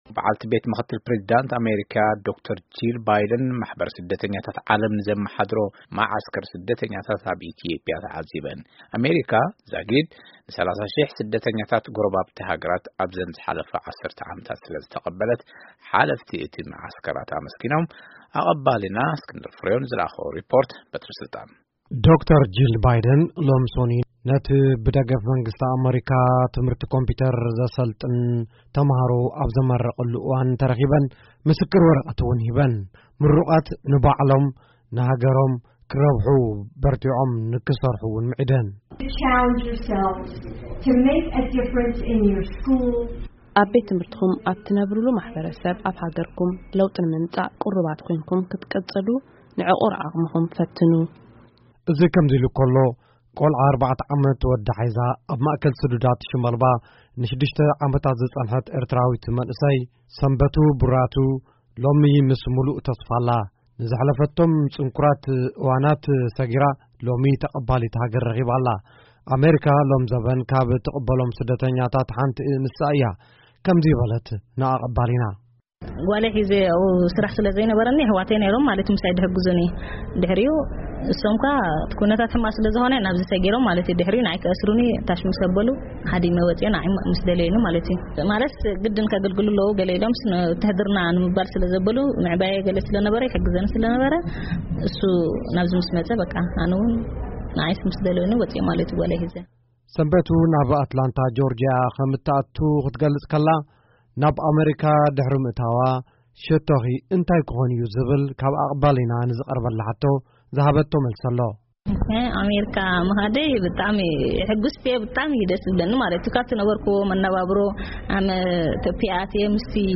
ሪፖርት መገሻ ዶ/ር ጂል ባይደን ኣብ ኢትዮጵያ